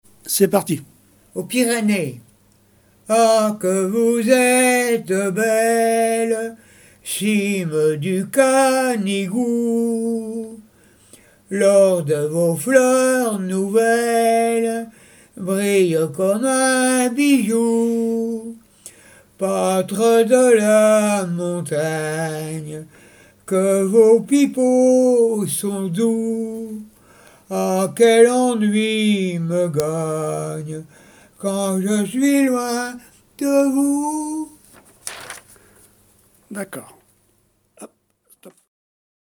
Genre strophique
chansons traditionnelles et d'école
Pièce musicale inédite